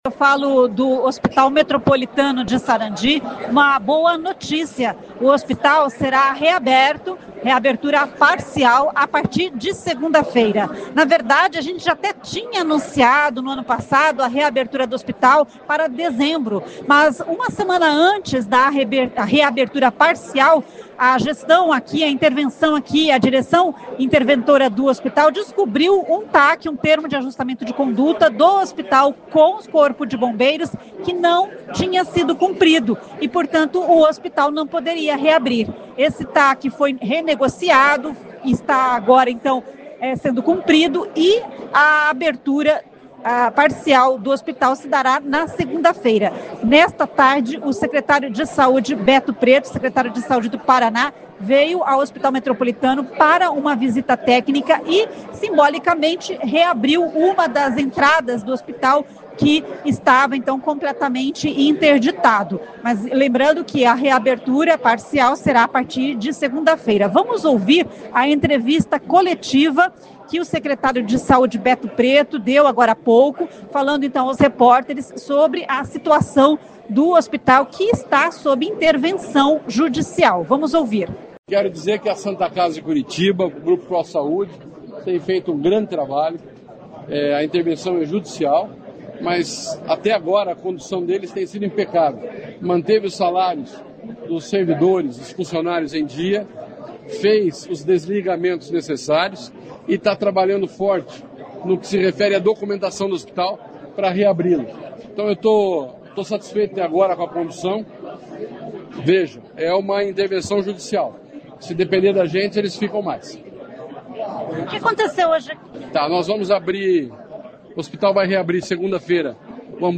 "A intervenção deve durar ainda um bom tempo", diz secretário de Saúde.